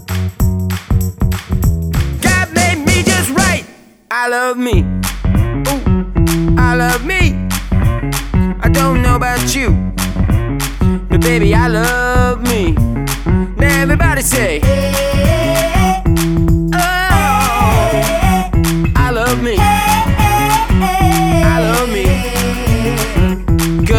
for solo female Pop (2010s) 2:46 Buy £1.50